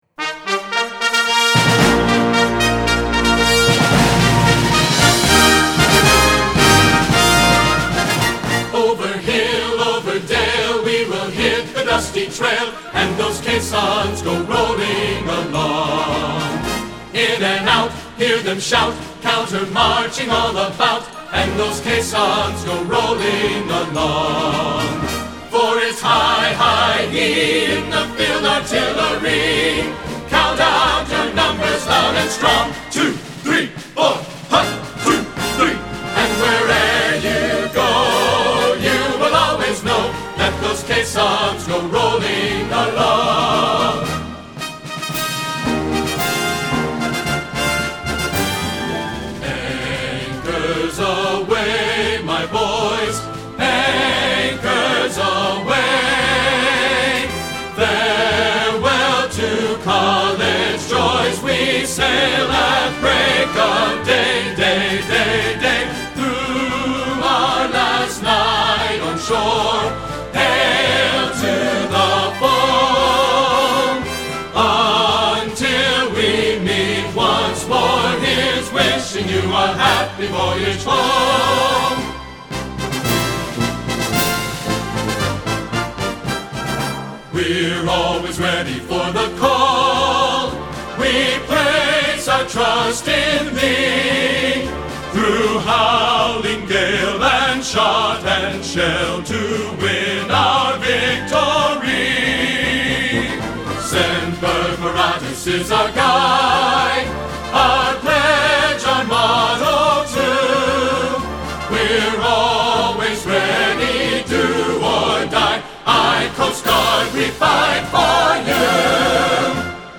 Tenor – Salute to the Armed Forces Hilltop Choir
Red, White, and Blue Sunday on July 5!